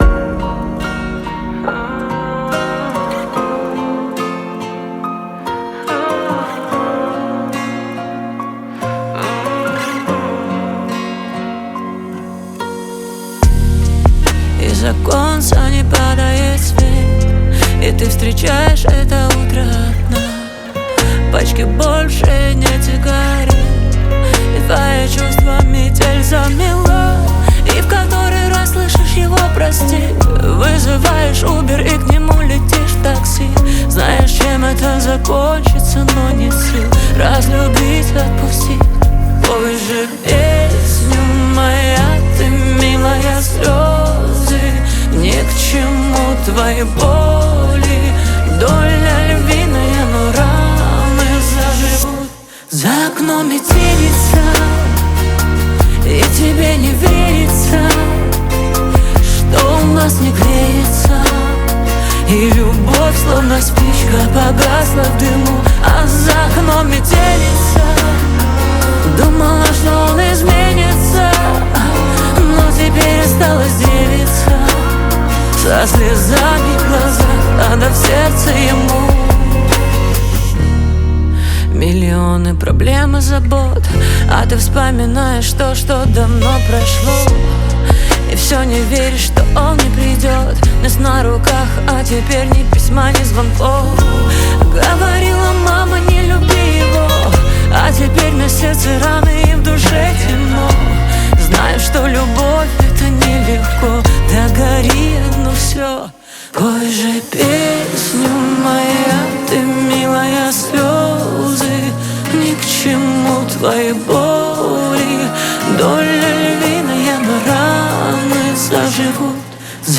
• Жанр: Украинская